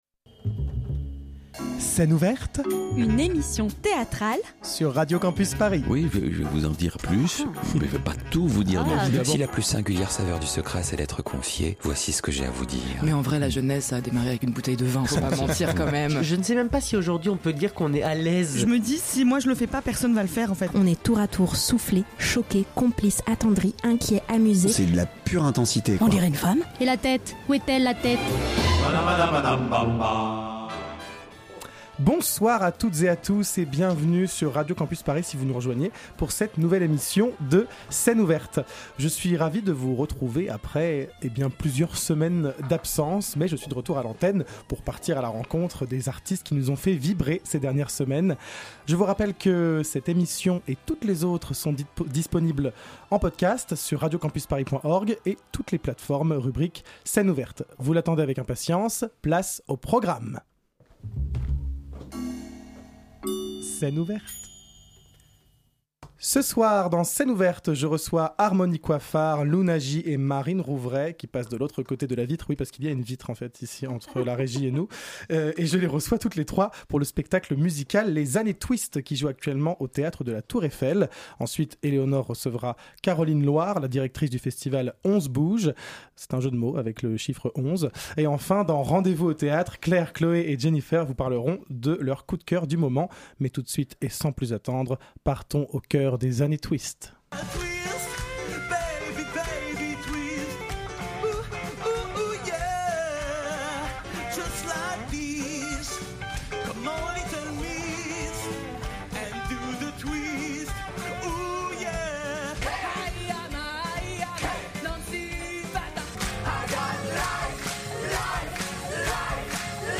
Ce soir, Scène Ouverte déborde d'énergie !
Type Magazine Culture